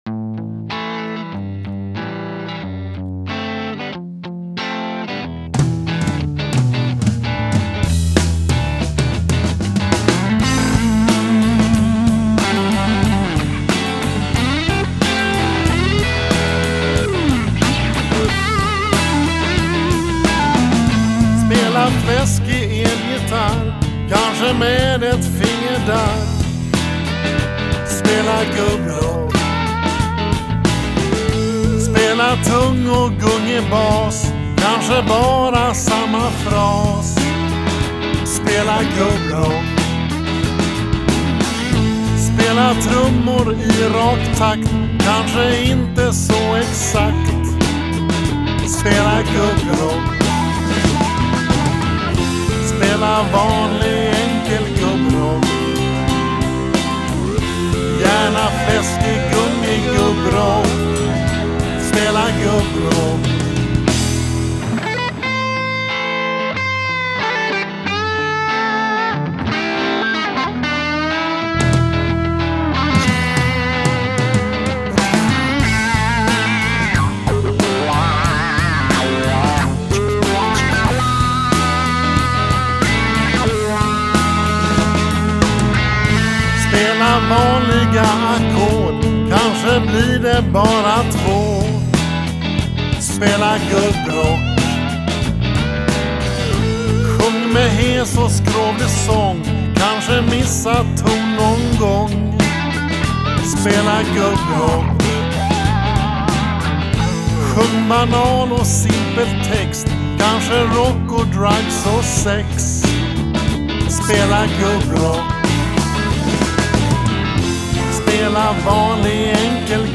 elgitarr
Jag enbart sjöng på låten.
bluesrocklåt